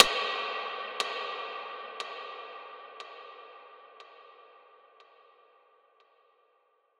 Big Drum Hit 12.wav